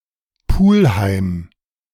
Pulheim (German pronunciation: [ˈpʊlhaɪm]
De-Pulheim.ogg.mp3